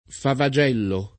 favagello
[ fava J$ llo ]